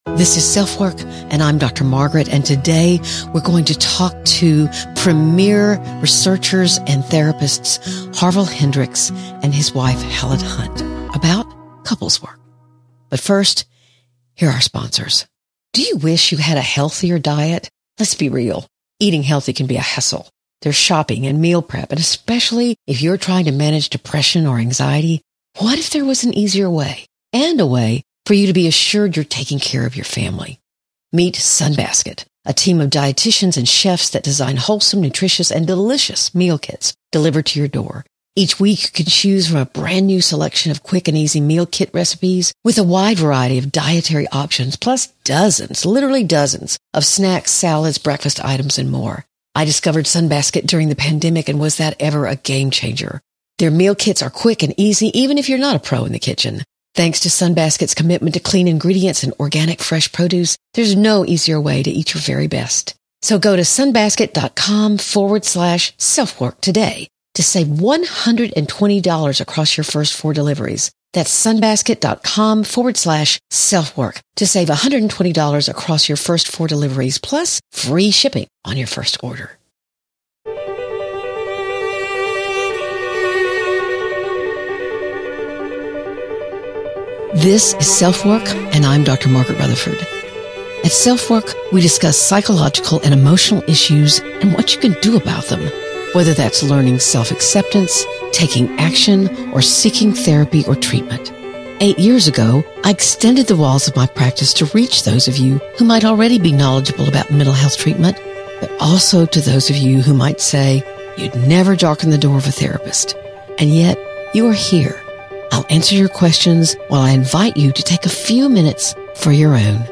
421 SelfWork: How Couples Therapy Began: A Conversation with Harville Hendrix and Helen Hunt (; 22 Nov 2024) | Padverb
In this intriguing interview, they talk about how working through their own conflict led them to defining the stages that they’re now famous for identifying.